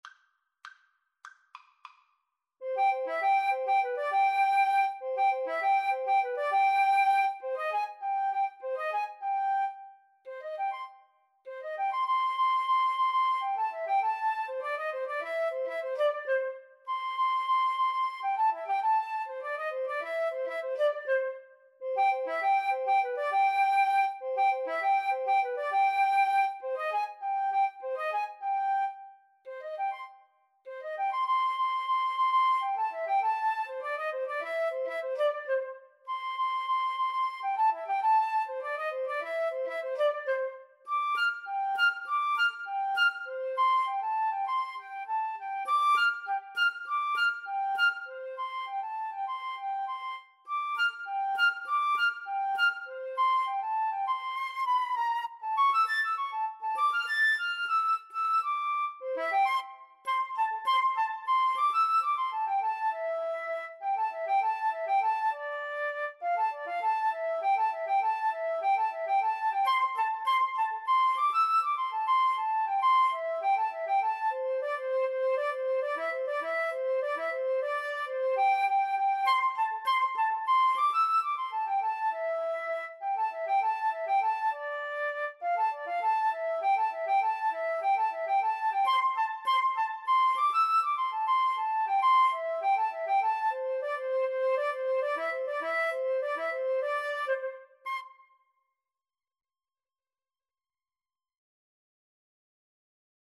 Energico =200
Flute Duet  (View more Intermediate Flute Duet Music)
Jazz (View more Jazz Flute Duet Music)